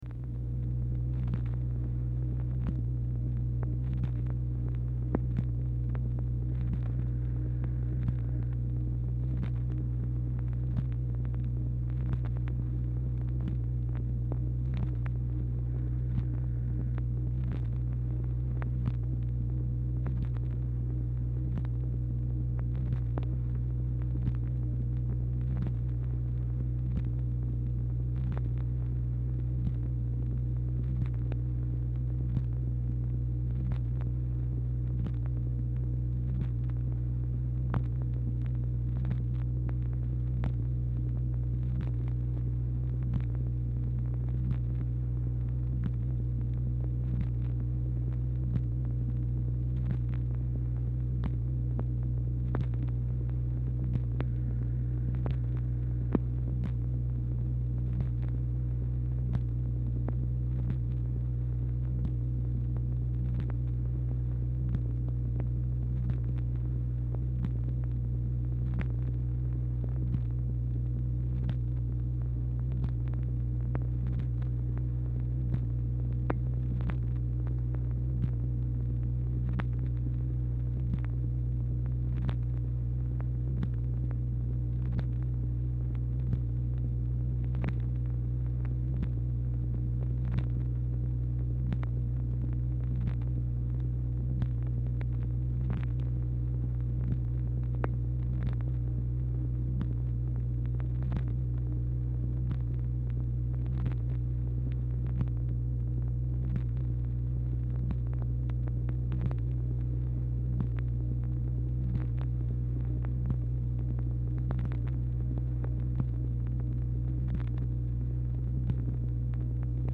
Telephone conversation # 798, sound recording, MACHINE NOISE, 12/30/1963, time unknown | Discover LBJ
Format Dictation belt